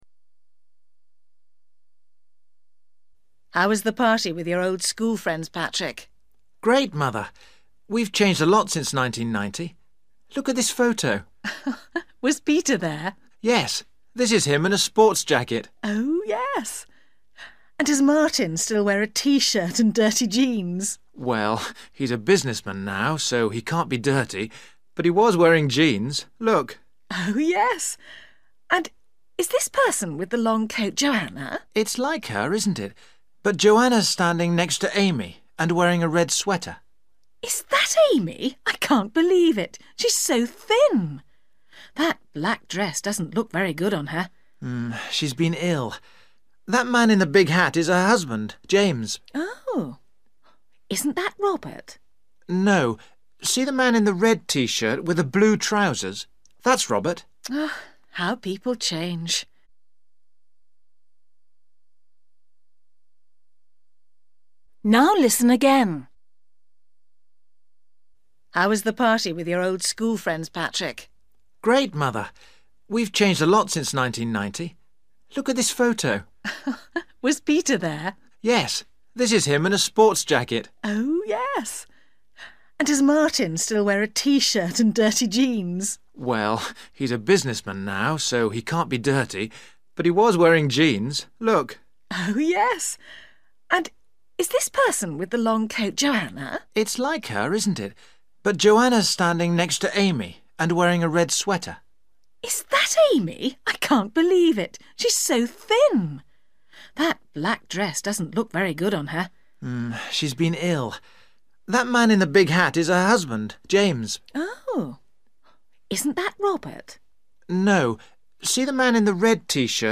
You will hear the conversation twice.